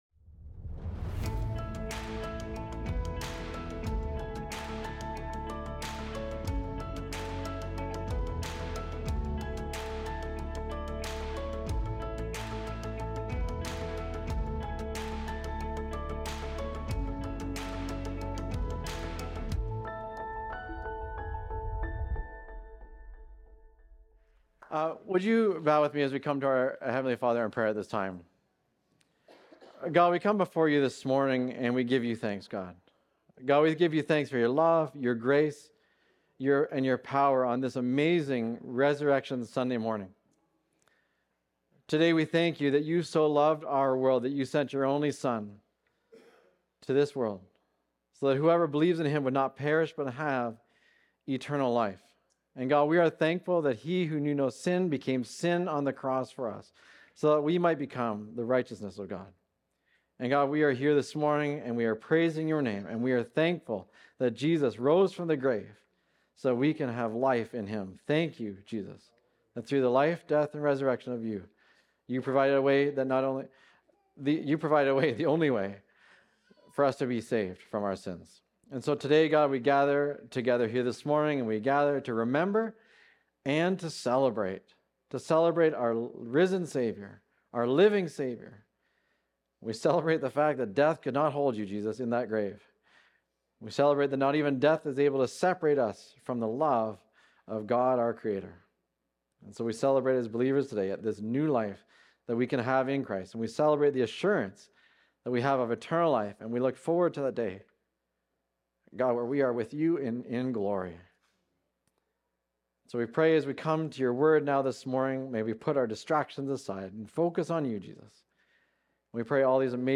Recorded Sunday, April 5, 2026, at Trentside Fenelon Falls.